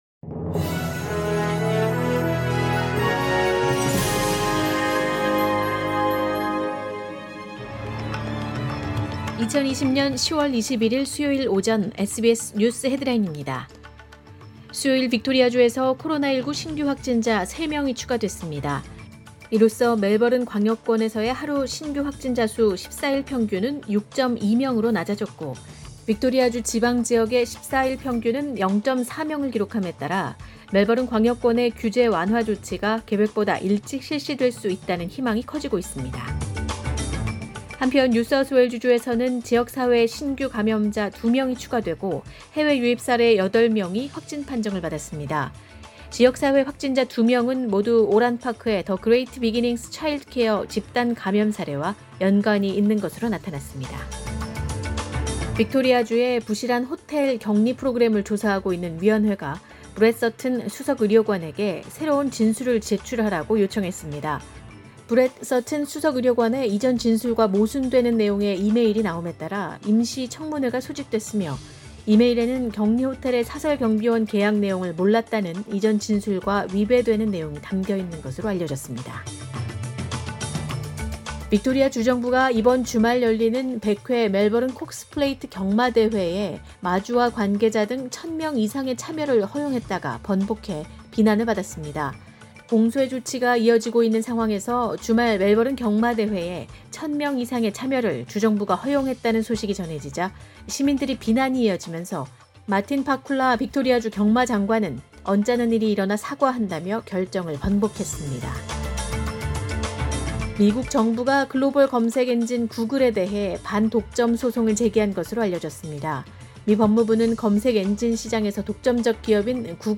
2020년 10월 21일 수요일 오전의 SBS 뉴스 헤드라인입니다.